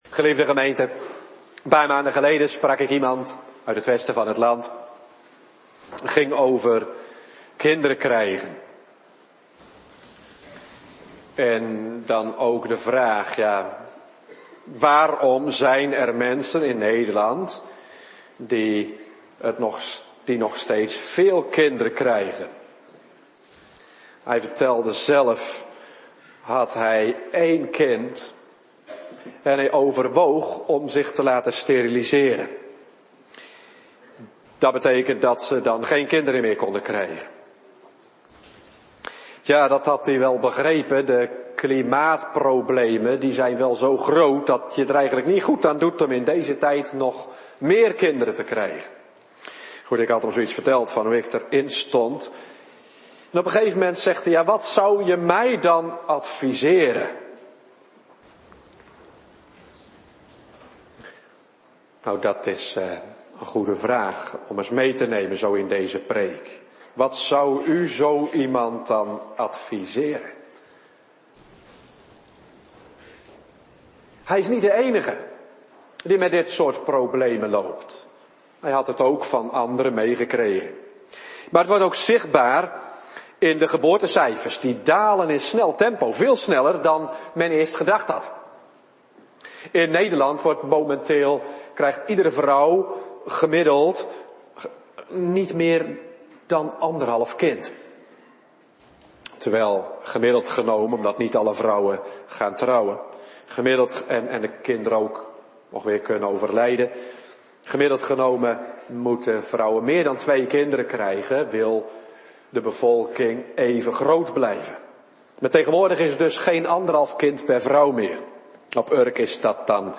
Soort Dienst: Bediening Heilige Doop